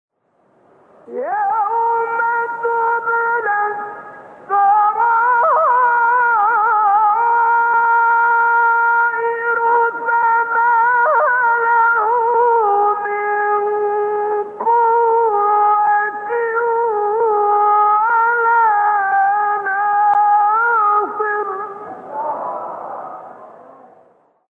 گروه شبکه اجتماعی: فرازهای صوتی اجرا شده در مقام حجاز با صوت کامل یوسف البهتیمی ارائه می‌شود.
برچسب ها: خبرگزاری قرآن ، ایکنا ، شبکه اجتماعی ، مقاطع صوتی ، مقام حجاز ، کامل یوسف البهتیمی ، قاری مصری ، تلاوت قرآن ، قرآن ، iqna